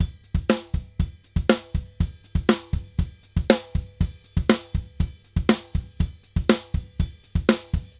Drums_Salsa 120_4.wav